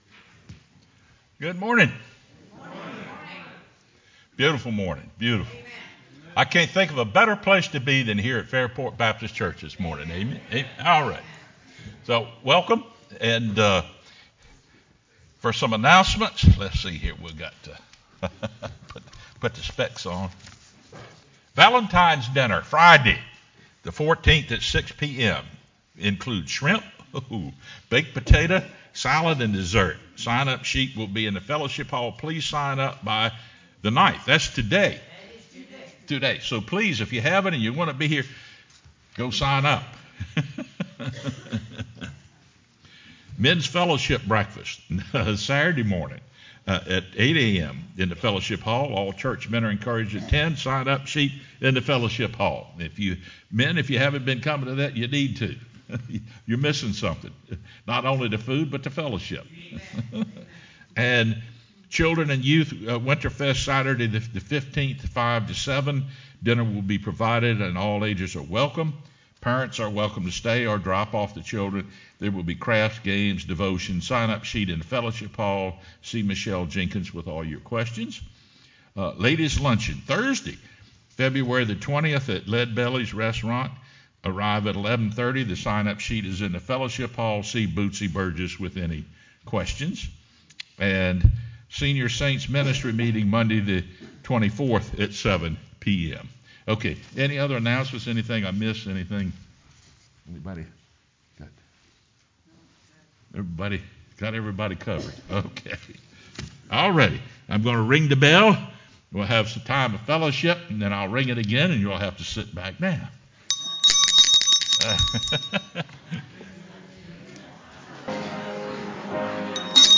sermonFeb09-CD.mp3